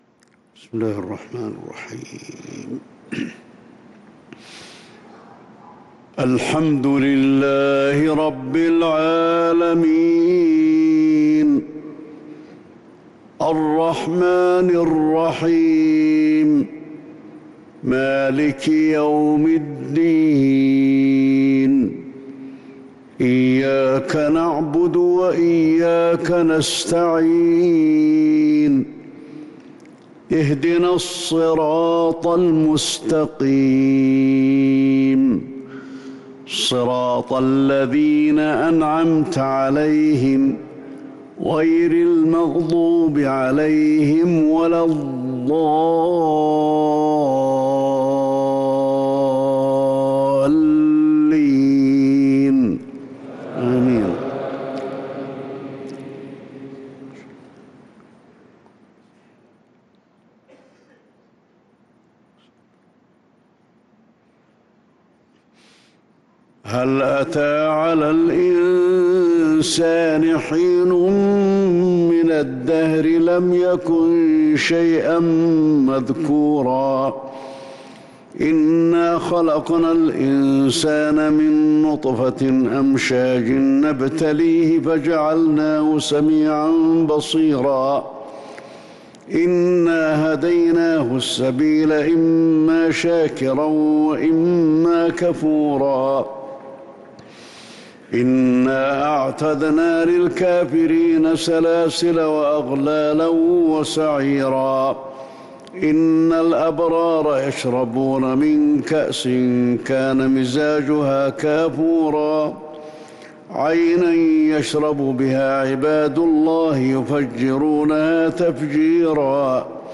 صلاة الفجر للقارئ علي الحذيفي 19 شوال 1443 هـ
تِلَاوَات الْحَرَمَيْن .